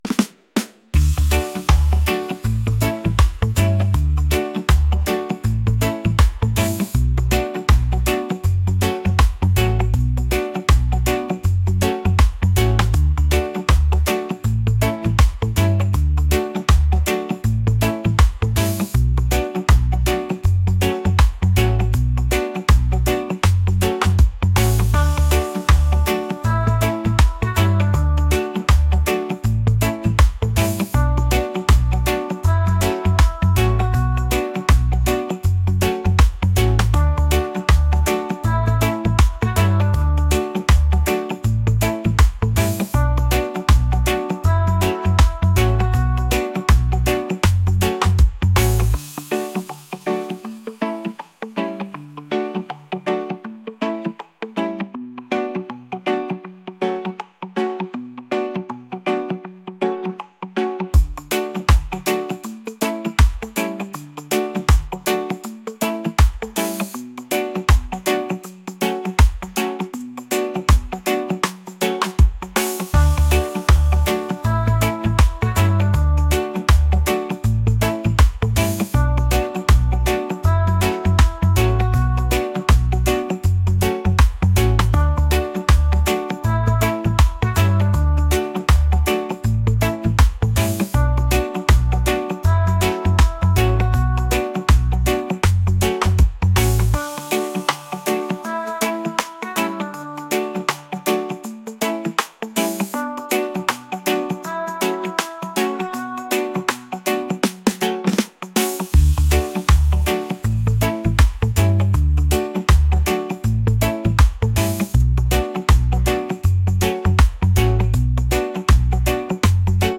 reggae | lofi & chill beats